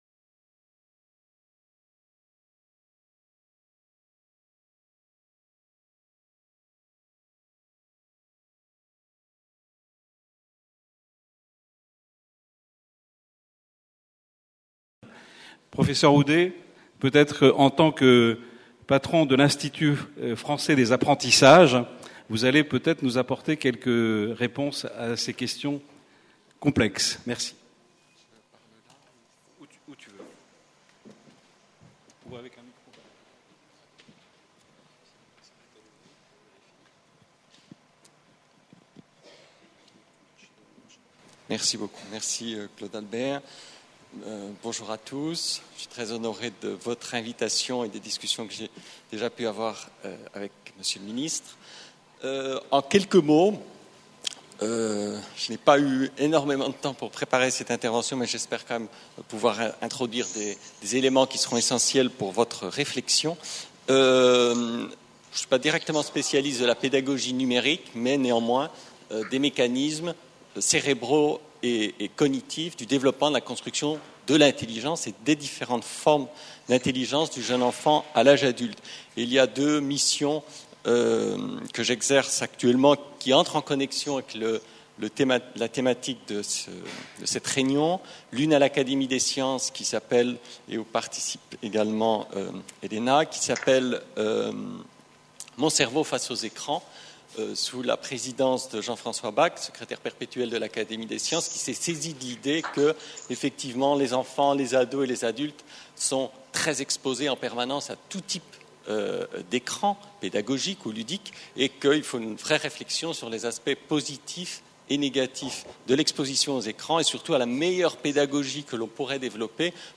PREMIER SÉMINAIRE INTERNATIONAL SANKORÉ DE RECHERCHE UNIVERSITAIRE SUR LA PÉDAGOGIE NUMÉRIQUE Conférence-Débat : INSERM / SANKORE : ZOOM SUR LES NEUROSCIENCESQue disent les neurosciences sur l’Education numérique ?